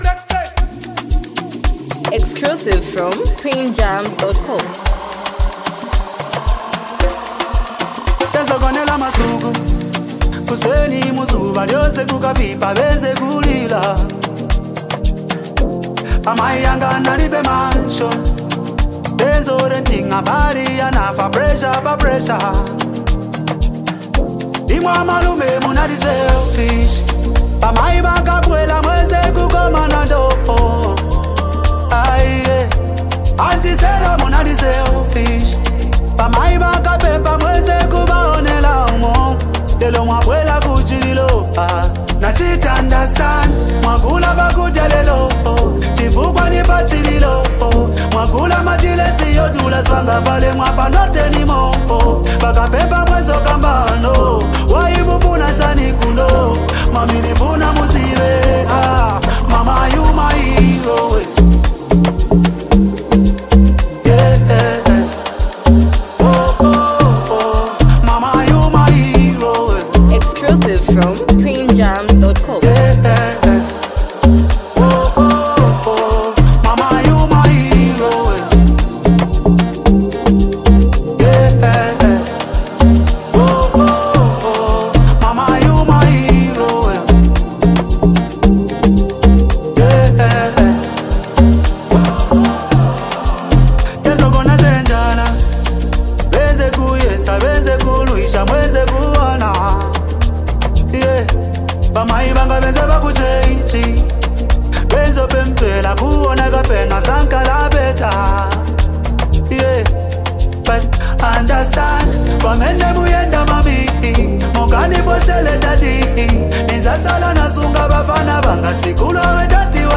emotional, hype, street vibes, and a powerful hook
unique singing flow